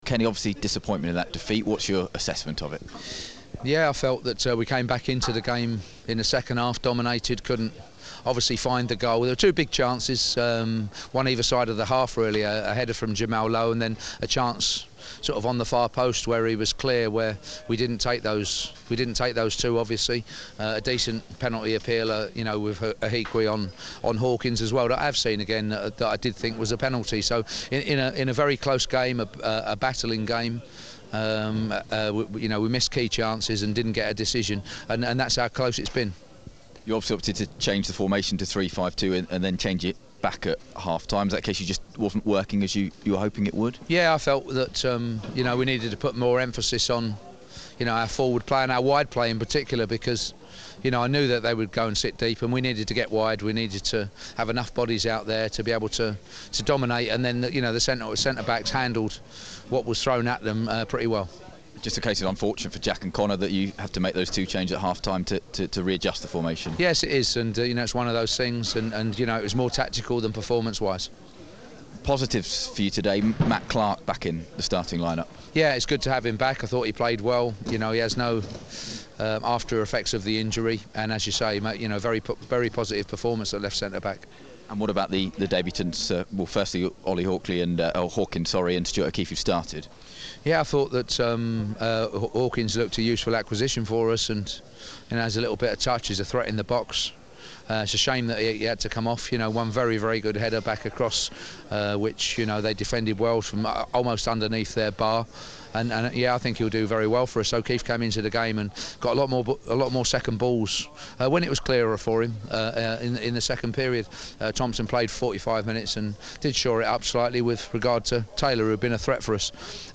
Kenny Jackett speaks after the loss at home to Rotherham